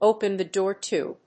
アクセントópen the dóor to…